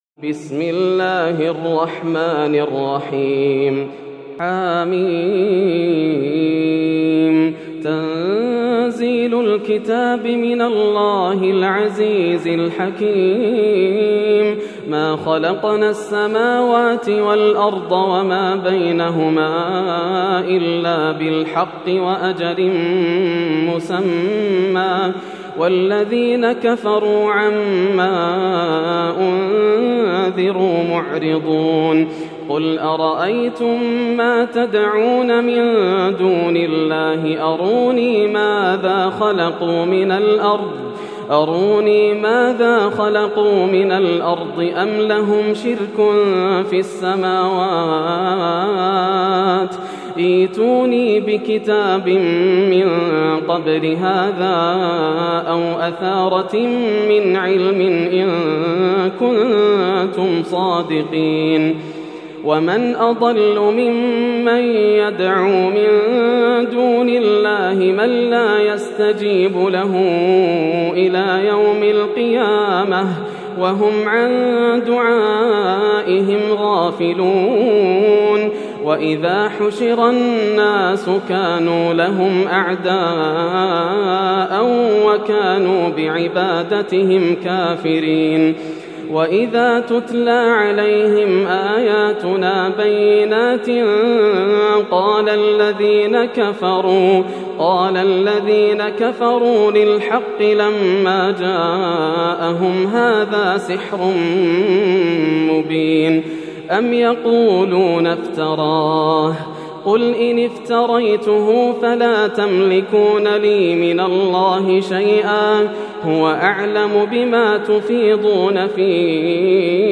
سورة الأحقاف > السور المكتملة > رمضان 1431هـ > التراويح - تلاوات ياسر الدوسري